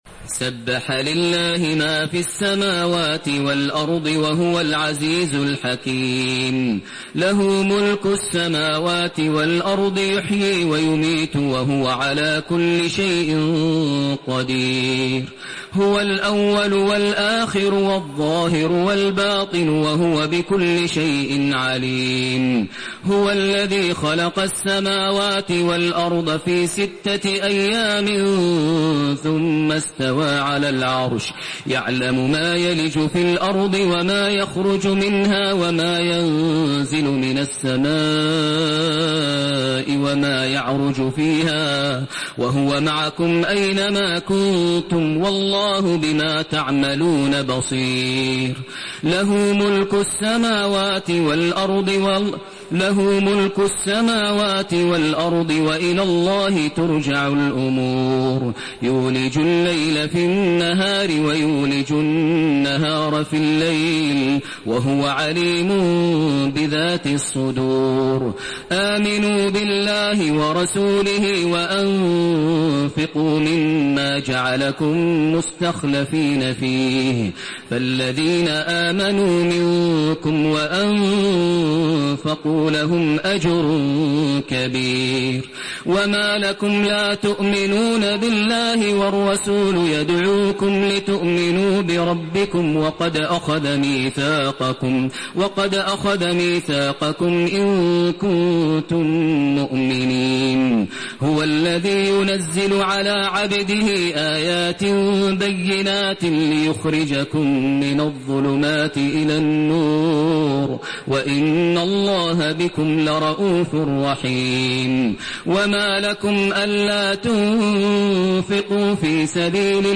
تحميل سورة الحديد بصوت تراويح الحرم المكي 1432
مرتل